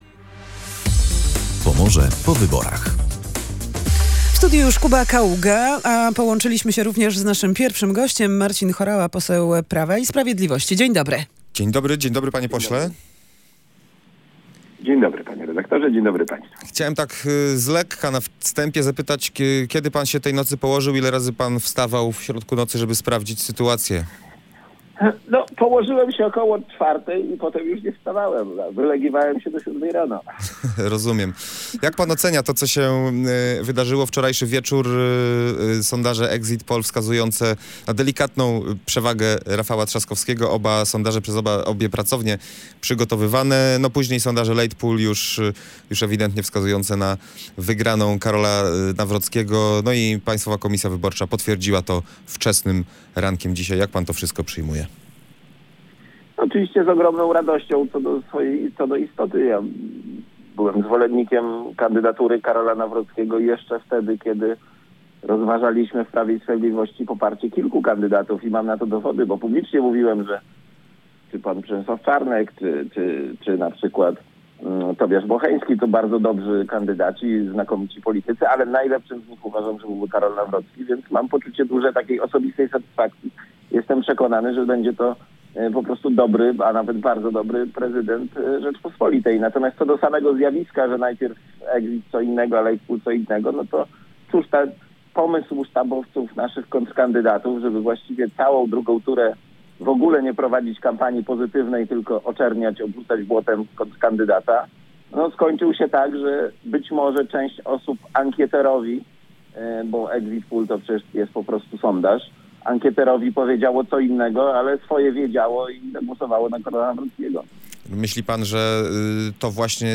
Pierwszym gościem powyborczego poranka w Radiu Gdańsk był Marcin Horała, poseł Prawa i Sprawiedliwości.